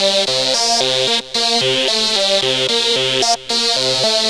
Track 15 - Synth 04.wav